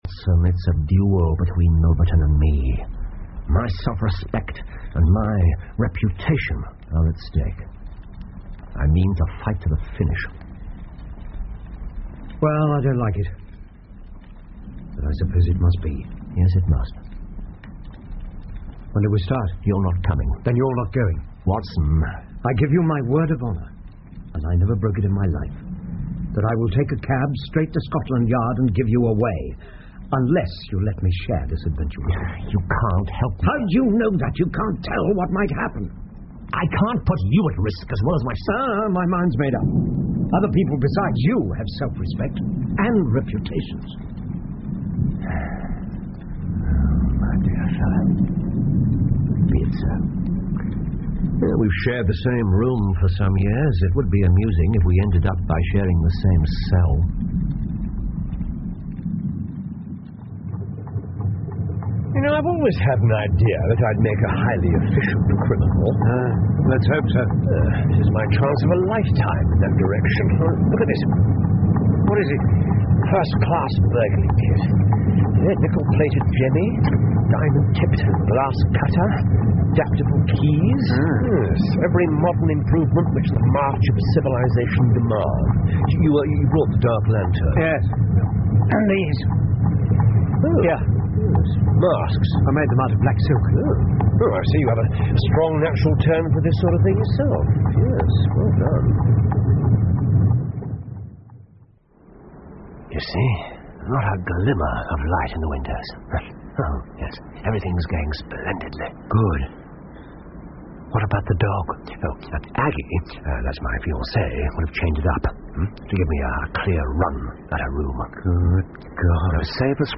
福尔摩斯广播剧 Charles Augustus Milverton 7 听力文件下载—在线英语听力室